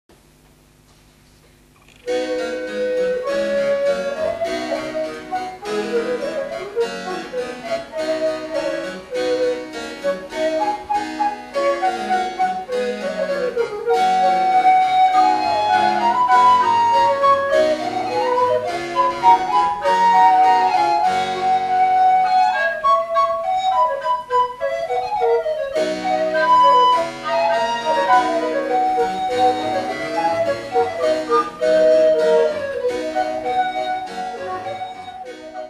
ウォーキングベースに乗った明朗なフーガ主題に、この空間の静かな喜ばしさが重なって、足取りも軽くなった。